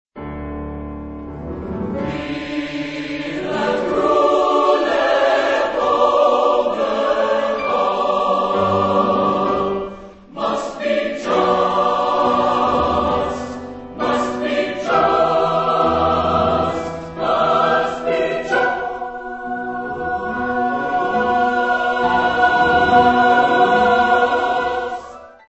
Género/Estilo/Forma: Sagrado
Tipo de formación coral: SATB  (4 voces Coro mixto )
Instrumentación: Piano  (1 partes instrumentales)
Tonalidad : sol mayor